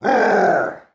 Sound of Bowser's roar from Mario & Luigi: Dream Team
MLDT_-_Bowser's_Roar.oga.mp3